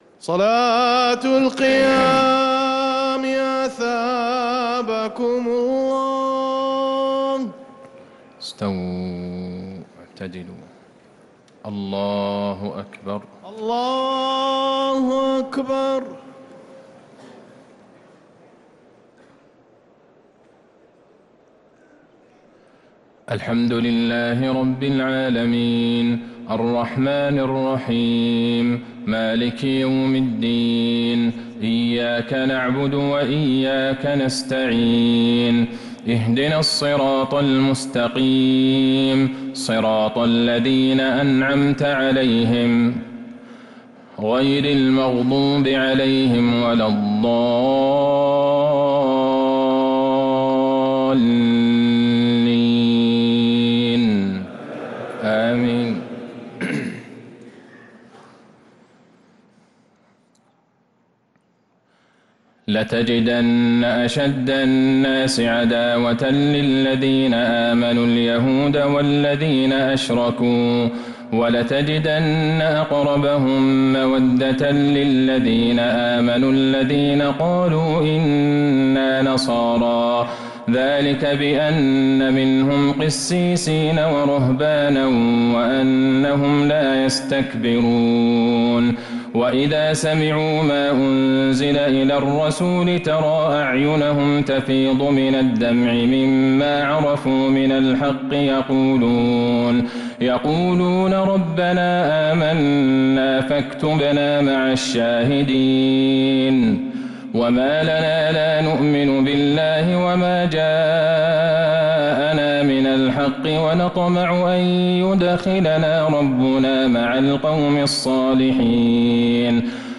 تراويح ليلة 9 رمضان 1447هـ من سورتي المائدة {82-120} و الأنعام {1-20} | Taraweeh 9th night Ramadan 1447H Surat Al-Ma'idah and Al-Ana'am > تراويح الحرم النبوي عام 1447 🕌 > التراويح - تلاوات الحرمين